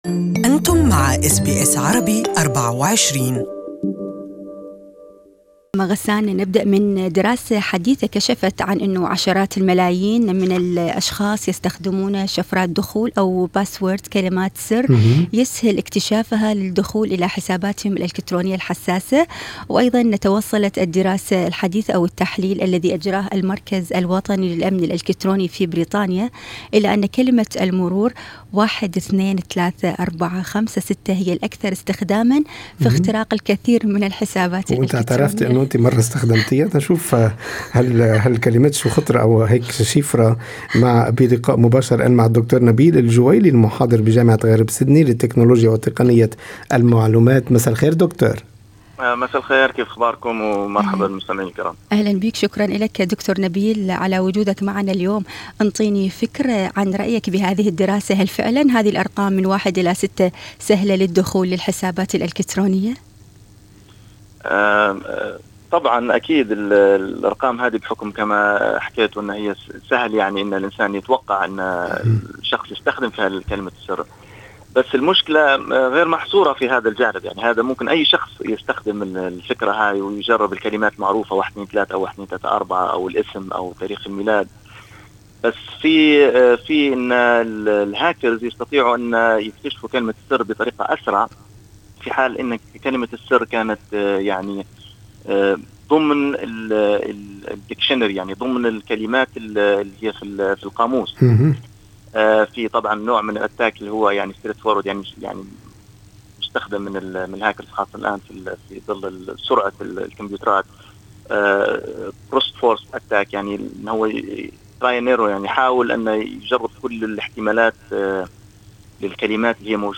المزيد في لقاءِ مباشر